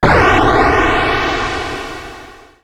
Shoot05.wav